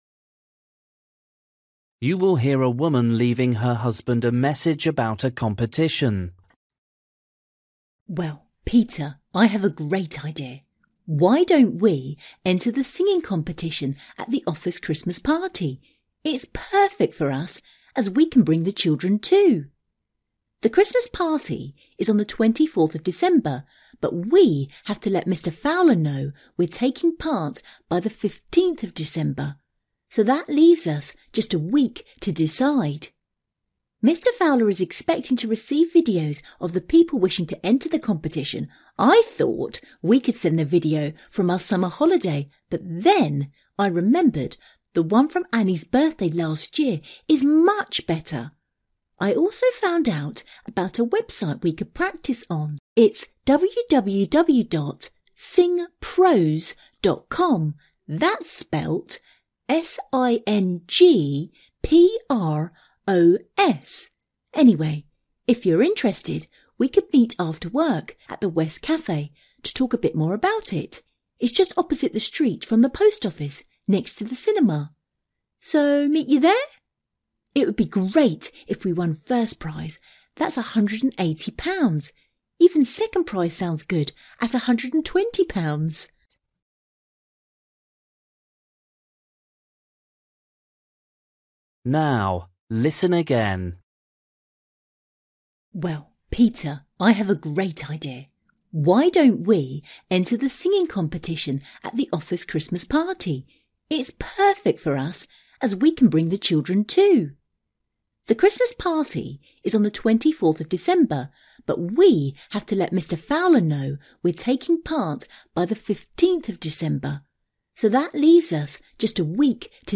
You will hear a woman leaving her husband a message about a competition.